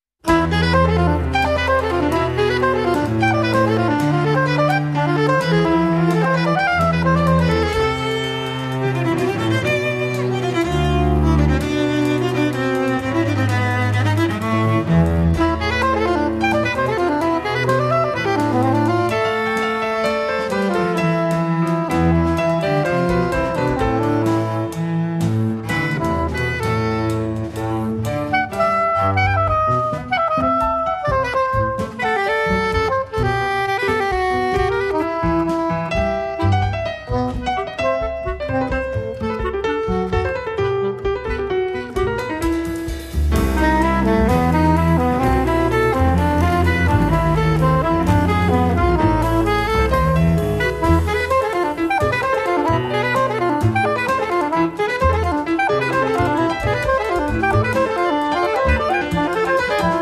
Tenor and soprano saxophones
Piano
double bass
drums